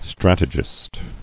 [strat·e·gist]